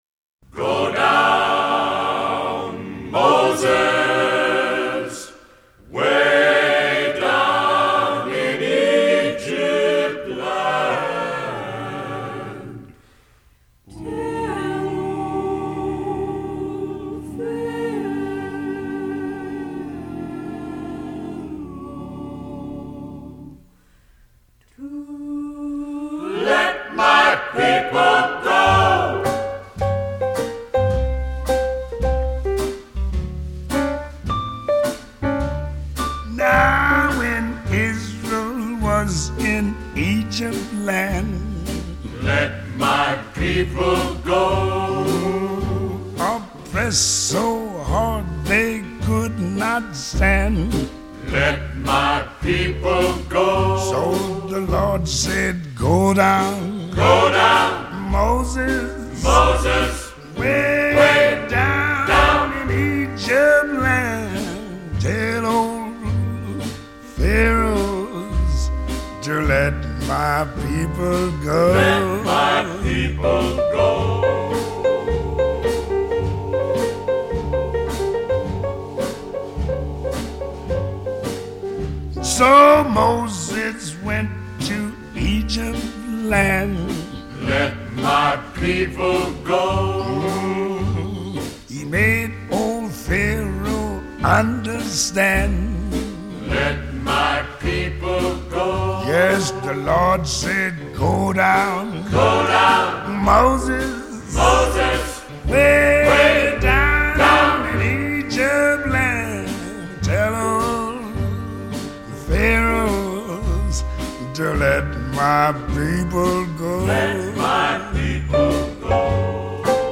ритм удивительный, затягивает!